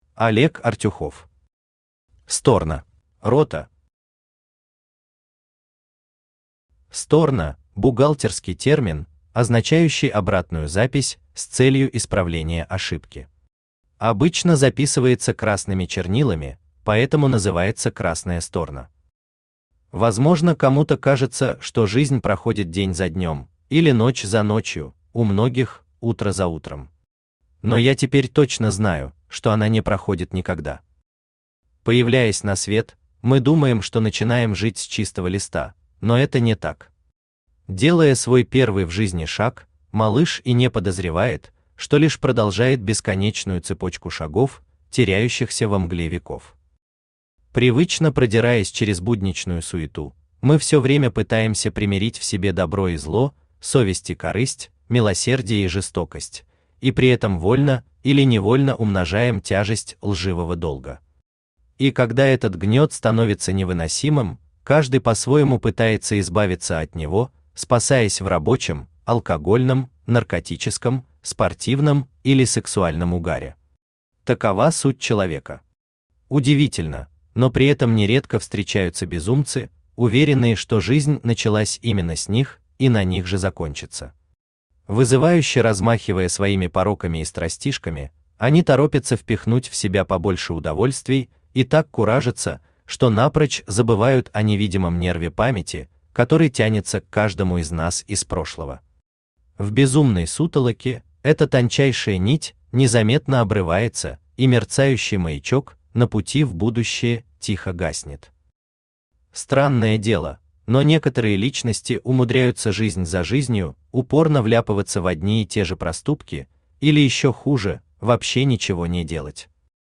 Аудиокнига Сторно | Библиотека аудиокниг
Aудиокнига Сторно Автор Олег Артюхов Читает аудиокнигу Авточтец ЛитРес.